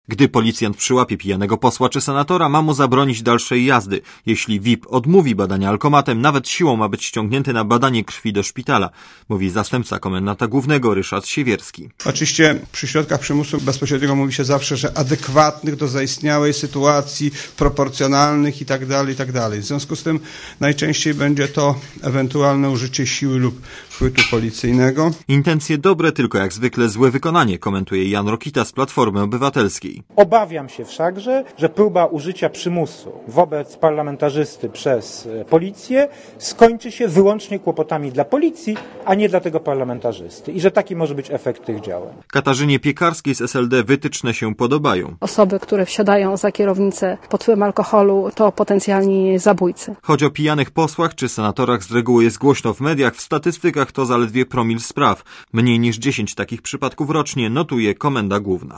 Relacja reportera Radia ZET Nadinsp.